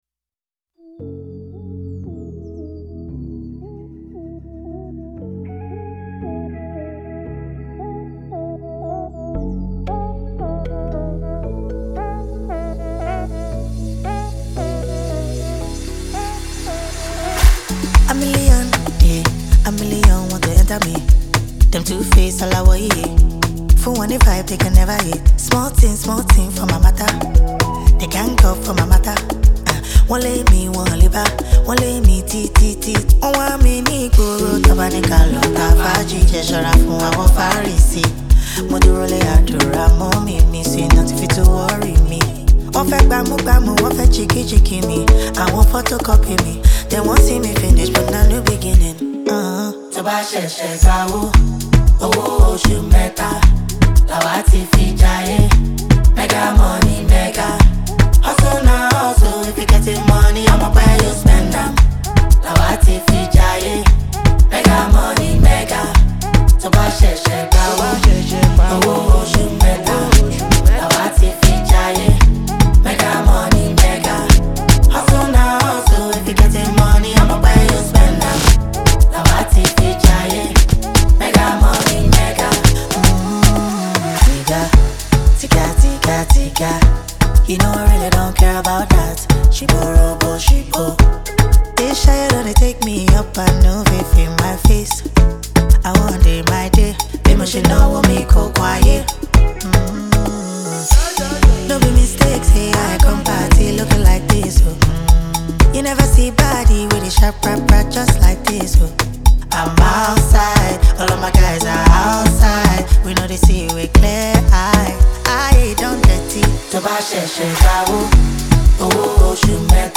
• Жанр: Soul, R&B